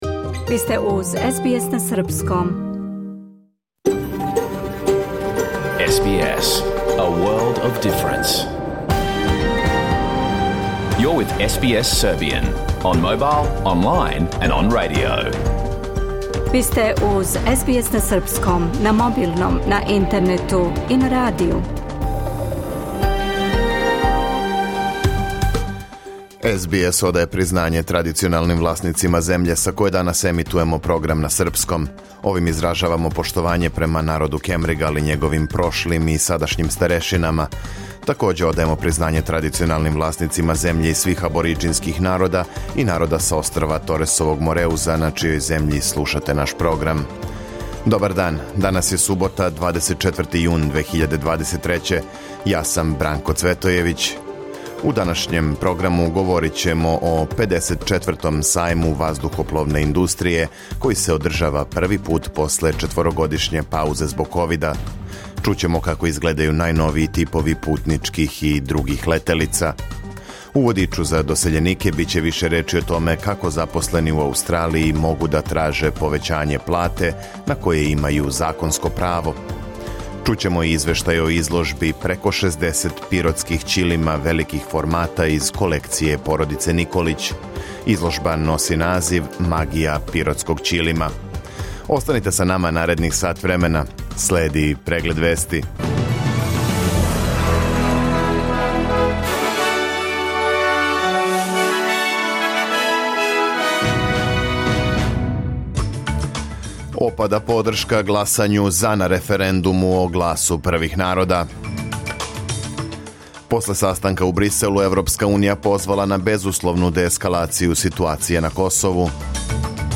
Програм емитован уживо 24. јуна 2023. године
Уколико сте пропустили данашњу емисију, можете је послушати у целини као подкаст, без реклама.